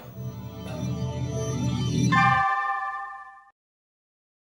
La source sonore n'est non seulement pas visible mais est un élément ajouté qui ne fait pas partie de l'action. Son but est de créer ou de souligner une ambiance, permettant au spectateur de mieux percevoir le message.